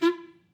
Clarinet
DCClar_stac_F3_v3_rr2_sum.wav